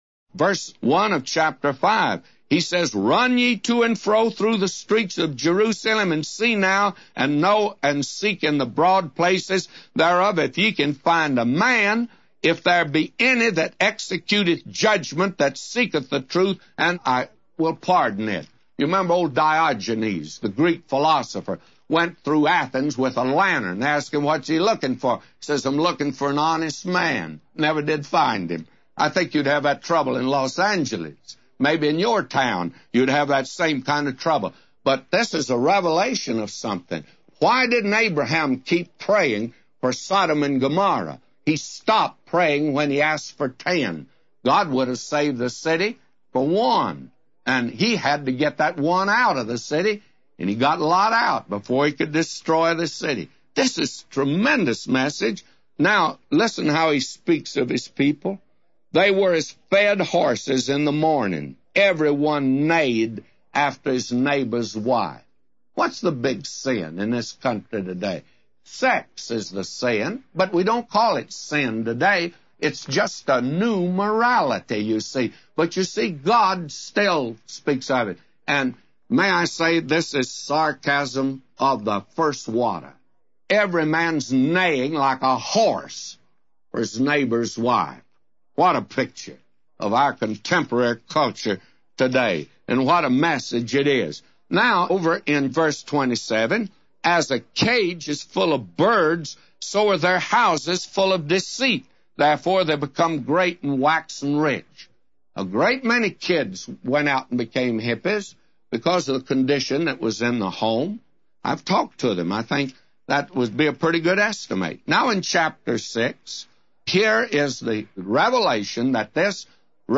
A Commentary By J Vernon MCgee For Jeremiah 5:1-999